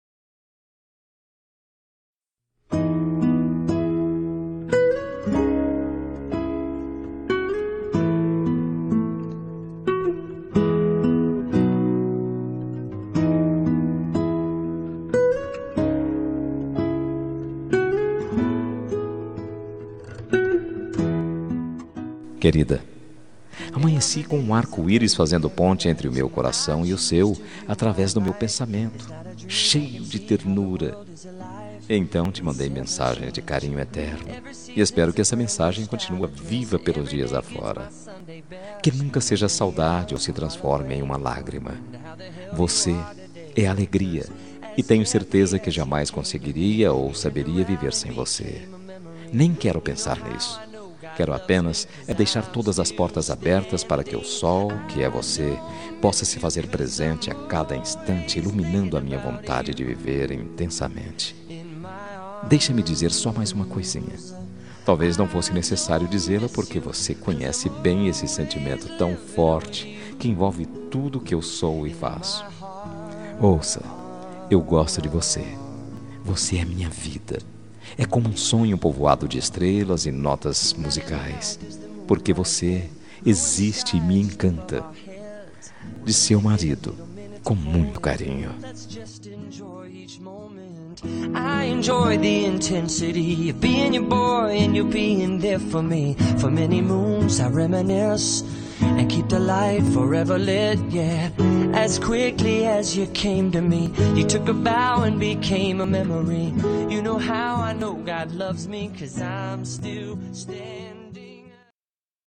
Telemensagem Romântica para Esposa – Voz Masculina – Cód: 201823